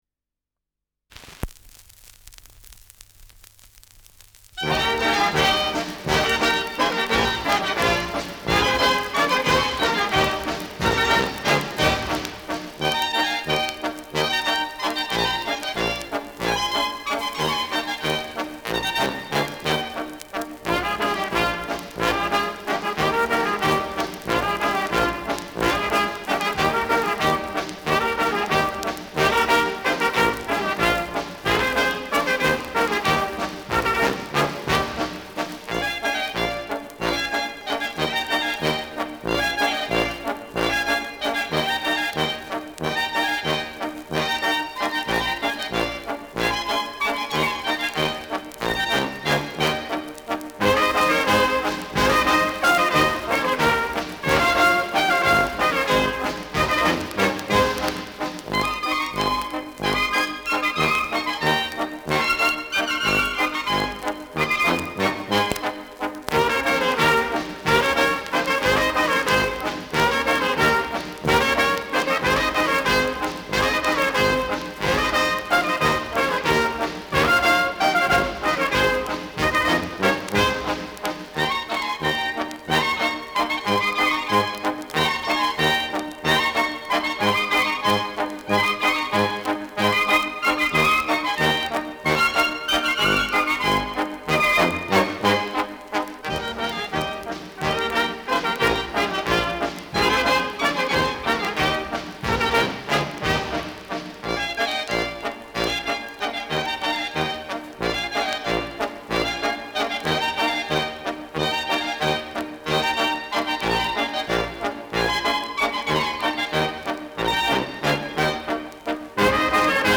Schellackplatte
Leichtes Grundknistern : Vereinzelt stärkeres Knacken
Kapelle Hallertau (Interpretation)